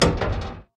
ambienturban_8.ogg